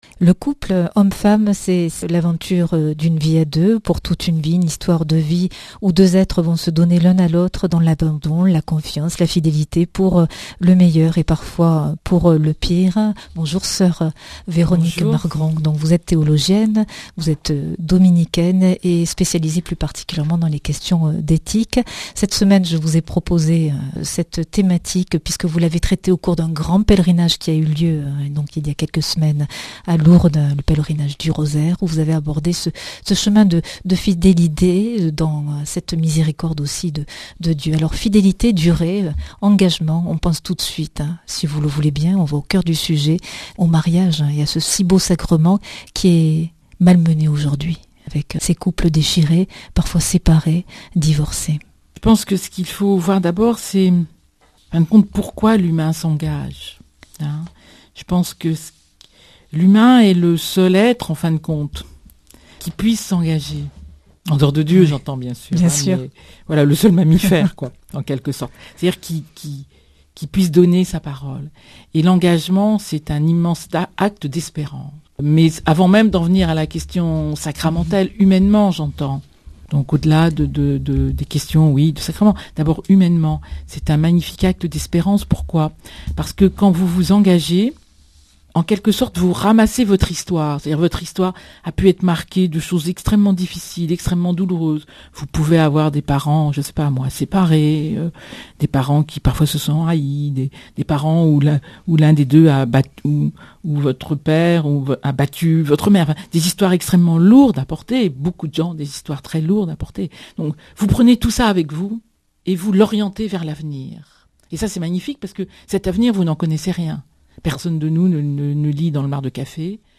Speech
Des clés pour vivre : Entretien 2 Invitée:Soeur Véronique Margron Pourquoi l’humain s’engage ?